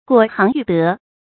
果行育德 注音： ㄍㄨㄛˇ ㄒㄧㄥˊ ㄧㄩˋ ㄉㄜˊ 讀音讀法： 意思解釋： 以果斷的行動培養高尚的道德。